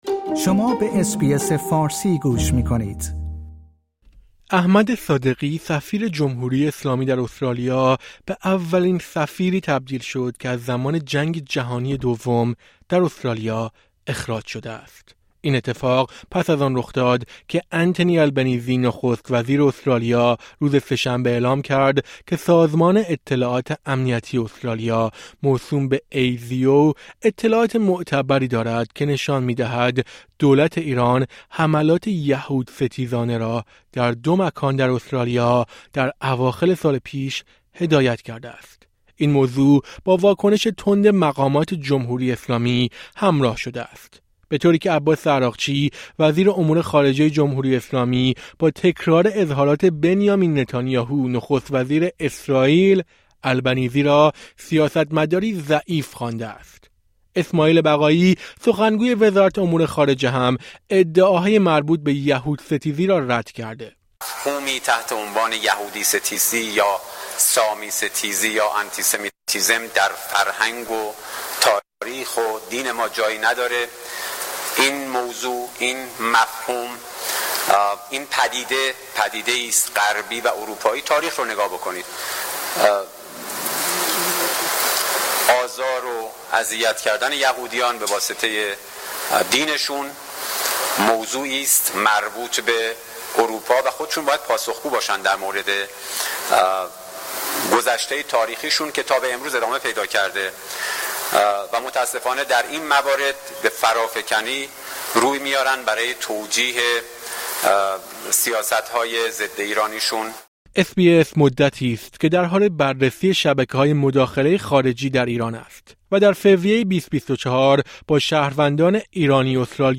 در فوریه سال پیش احمد صادقی، سفیر وقت ایران در استرالیا در گفت‌وگویی با اس‌بی‌اس فارسی ادعاهای مربوط به دخالت خارجی جمهوری اسلامی در استرالیا را رد کرد.